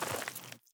added stepping sounds
Mud_Mono_04.wav